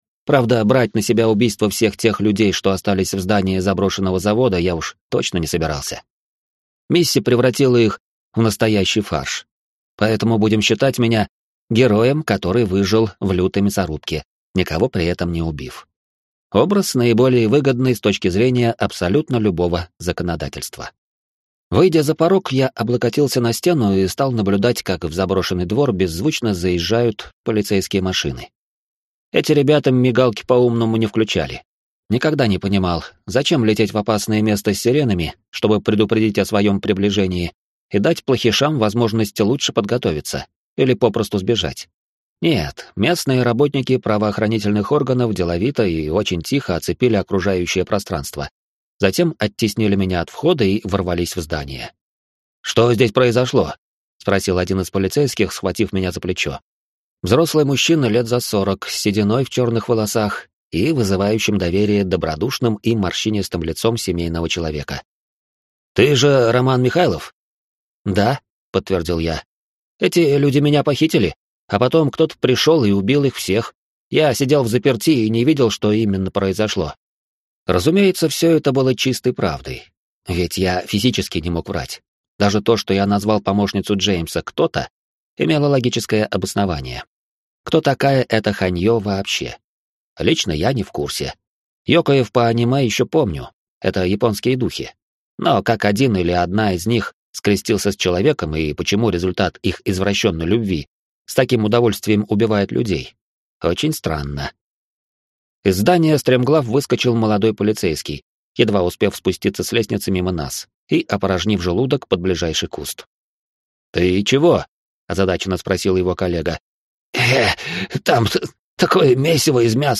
Аудиокнига Кулак Полуденной Звезды. Приманка для призраков | Библиотека аудиокниг